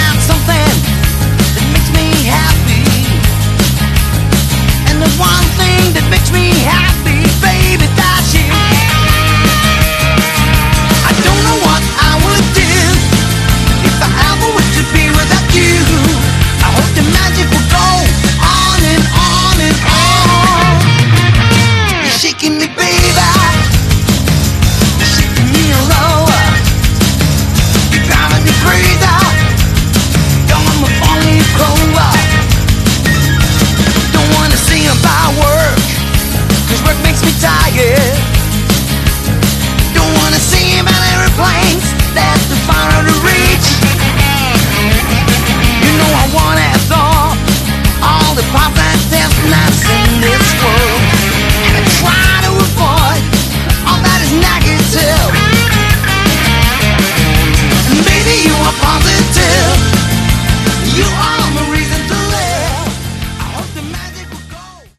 Category: AOR
lead vocals, guitar
drums